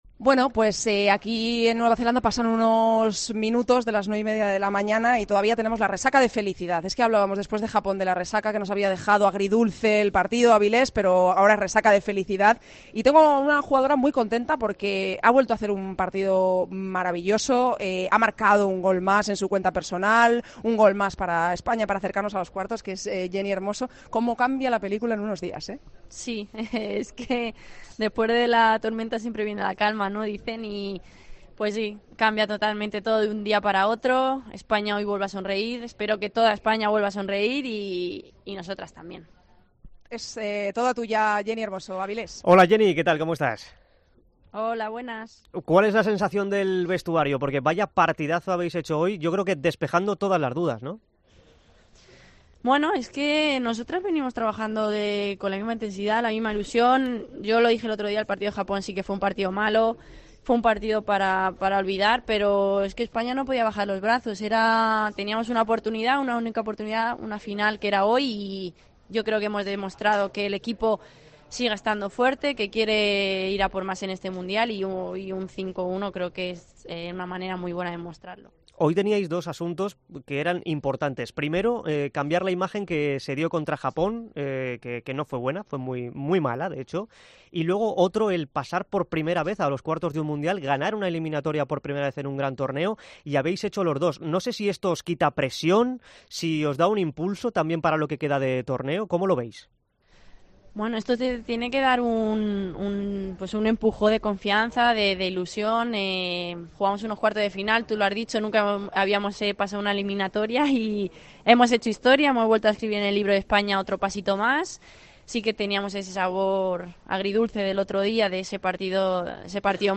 Con Paco González, Manolo Lama y Juanma Castaño